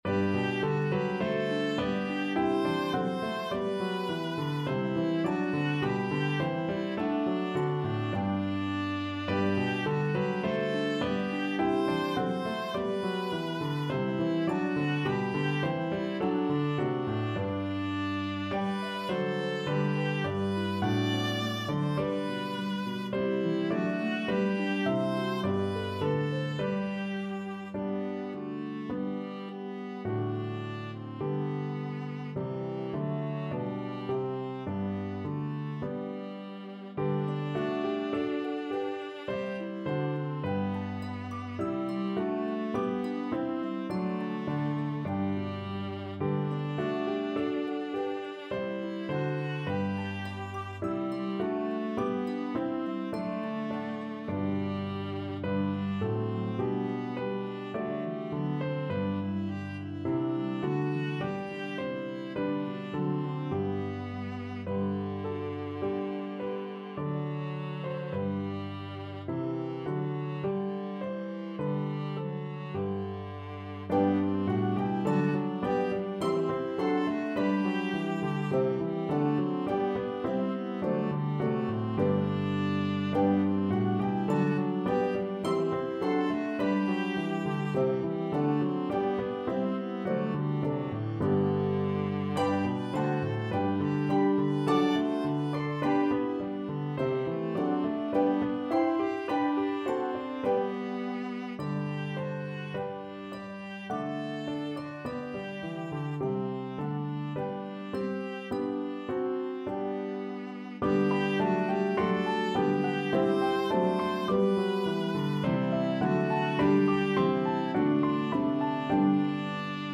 The lyrics to the German Epiphany hymn